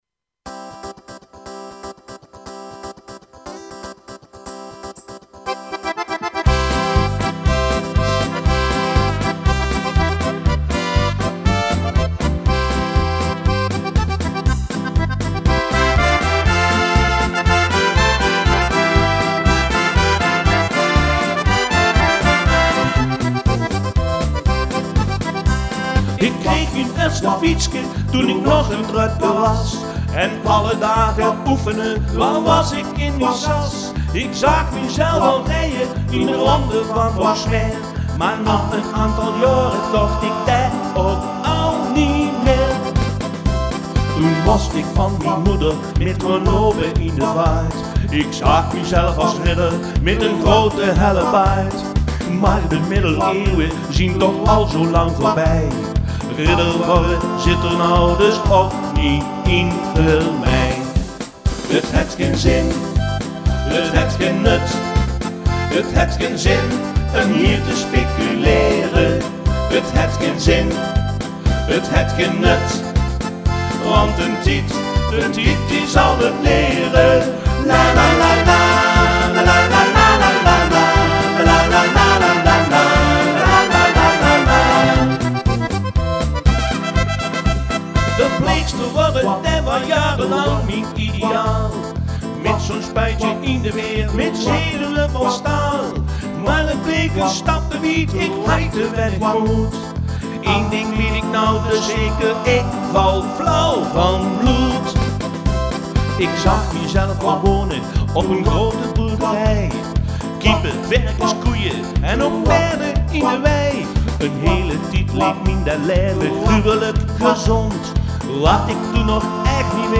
Pronkzitting 2012